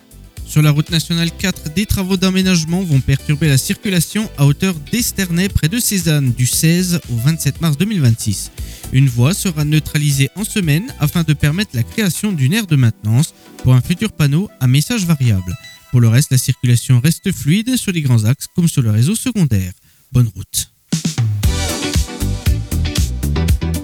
Bienvenue dans l’InfoRoute des Plaines – votre bulletin circulation du matin !